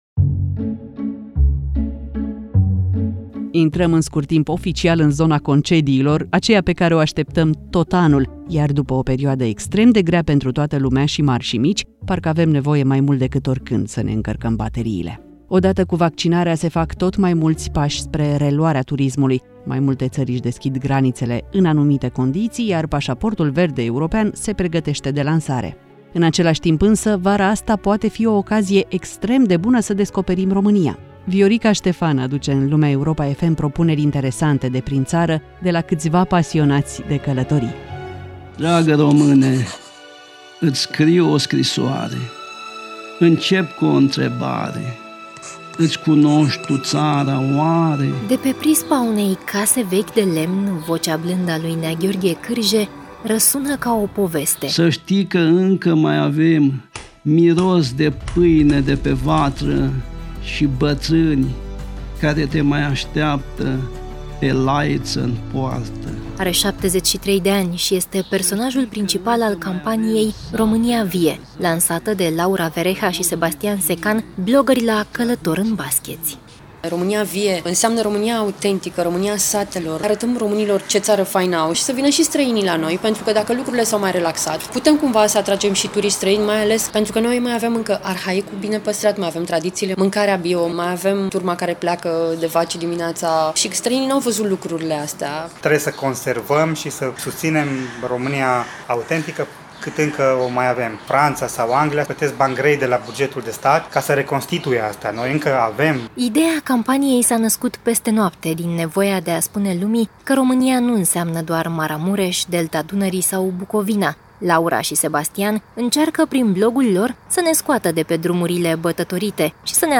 Lumea Europa FM: Destinații de neratat vara aceasta în România | Reportaj